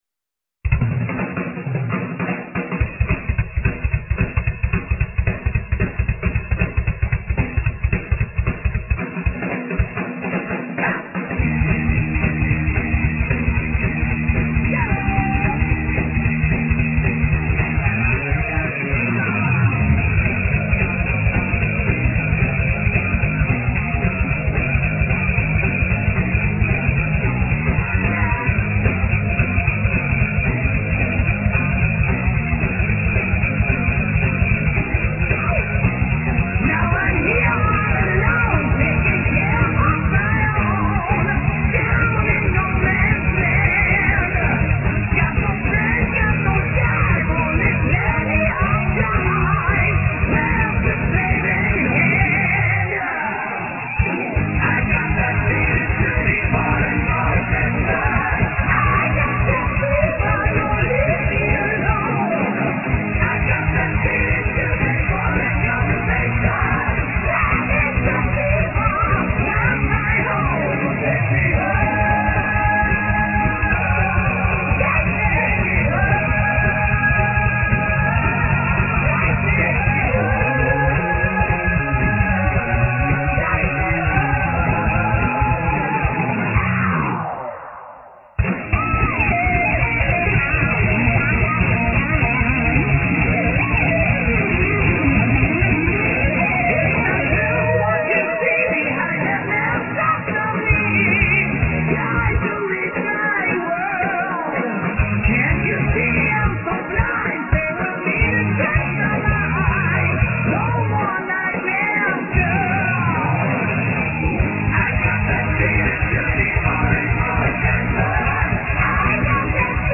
Vocals
Guitars
Bass
Drums
Keyboards